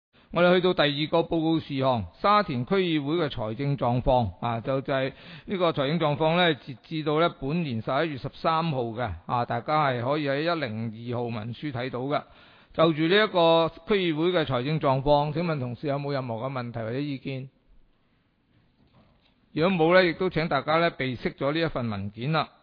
区议会大会的录音记录
地点: 沙田区议会会议室